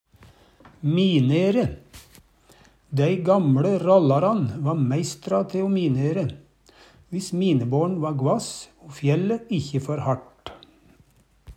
minere - Numedalsmål (en-US)